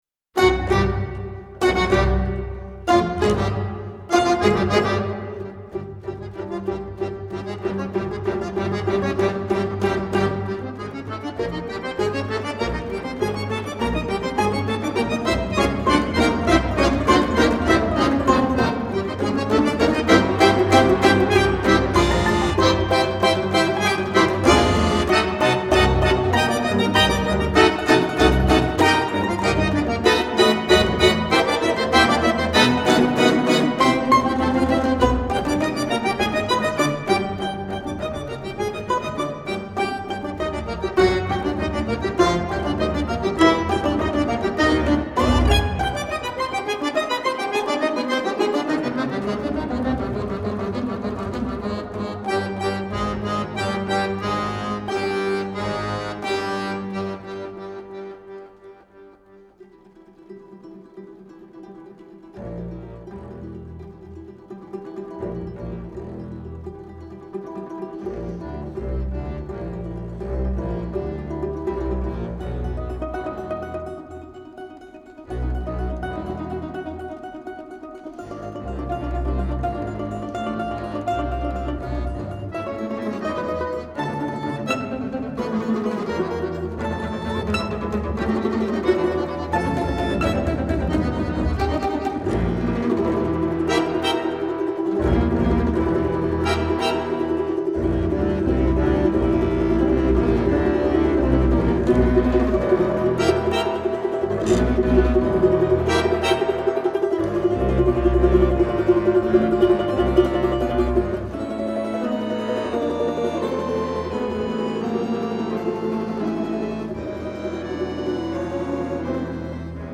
Произведение состоит из 10 фортепианных пьес (картин), разделённых музыкальными променадами, которые играют роль связующего элемента между частями.
Используя напряженные диссонансы, композитор рисует яркую картину, подчеркивающую демоническую сущность Бабы-Яги, присутствие загадочного леса, полёта на метле и погони за безымянным героем русской сказки.